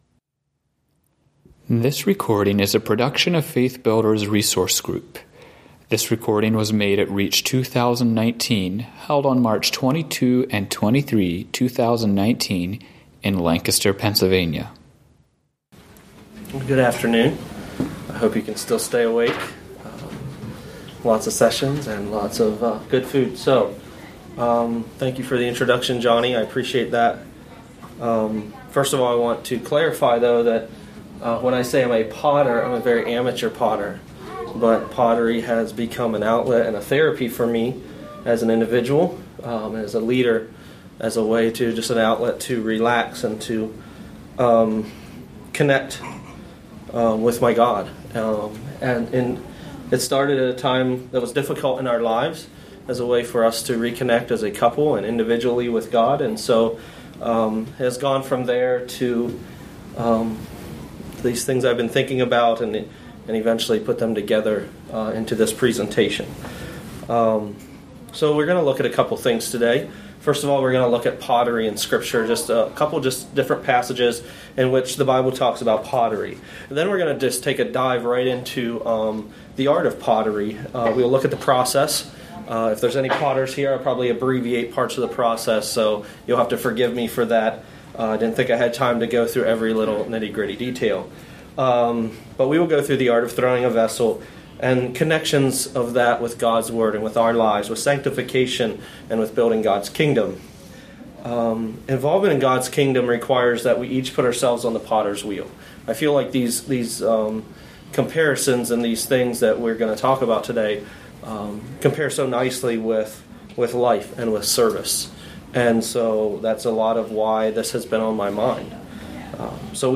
Home » Lectures » As Clay in the Hands of a Potter: Life Lessons from a Potter